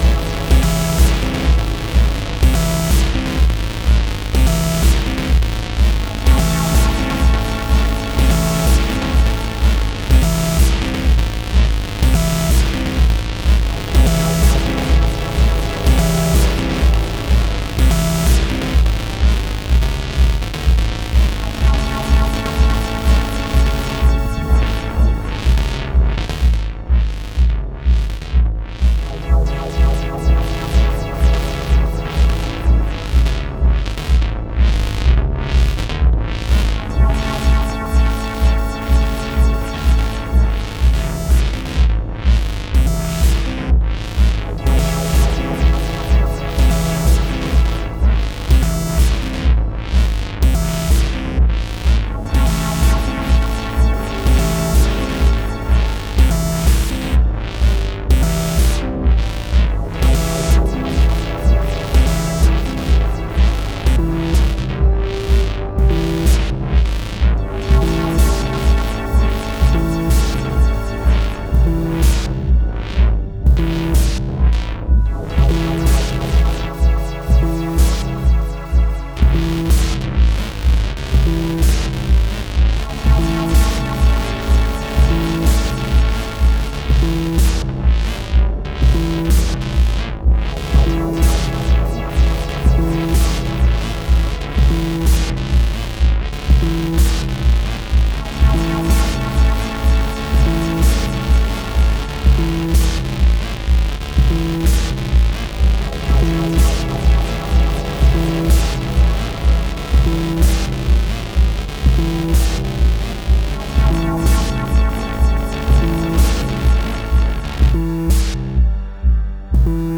acid jam session 3-5